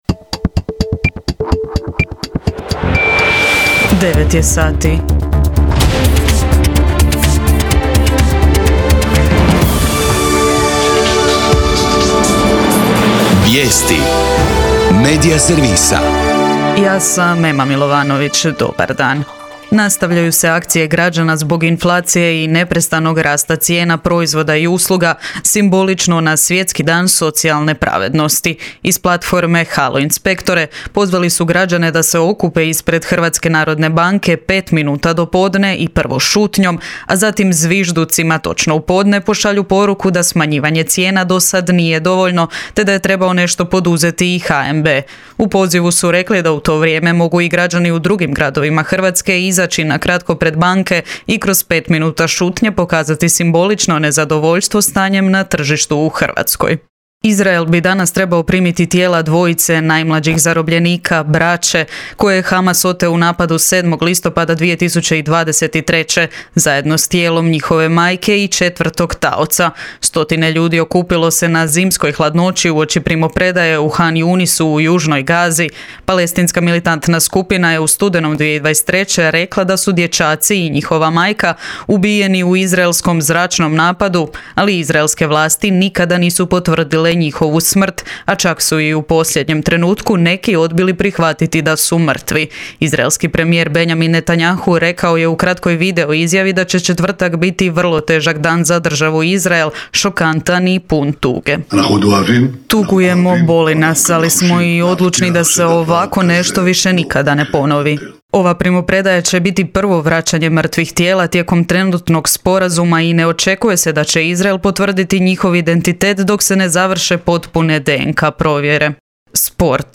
VIJESTI U 9